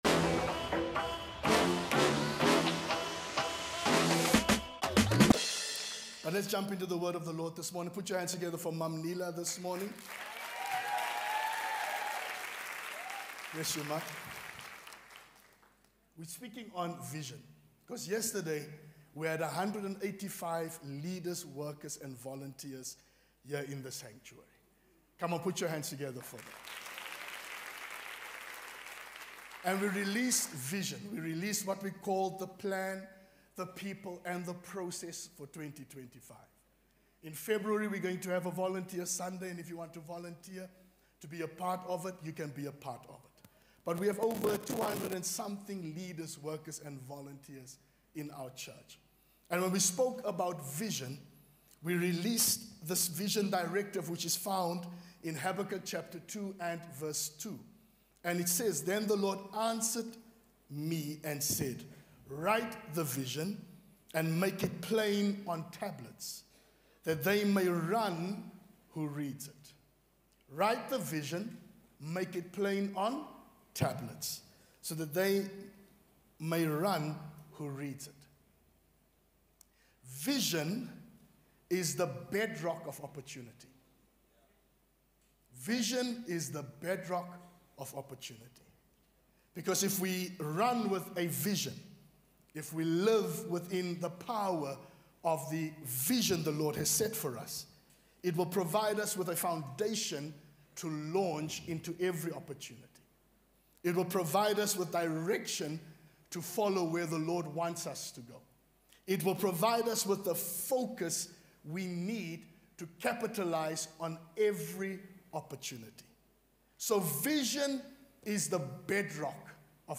brings the message on Vision for us!